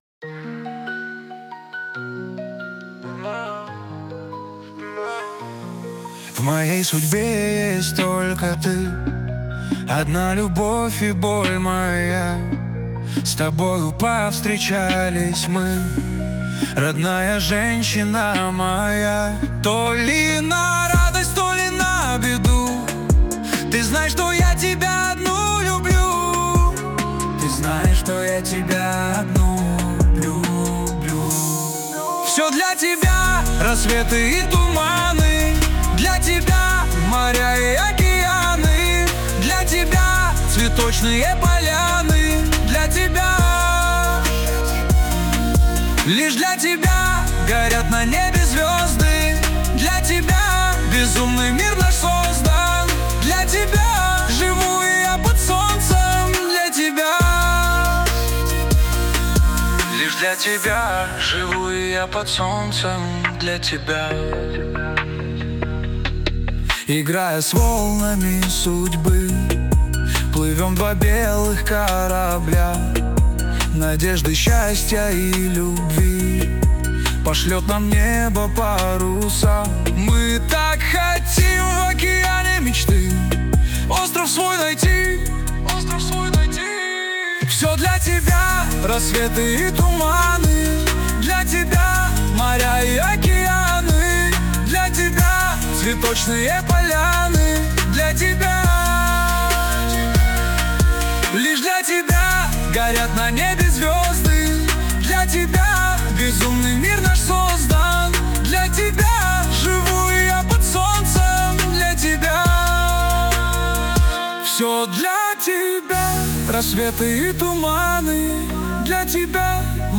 RUS, Dance, Pop, Disco | 16.03.2025 10:06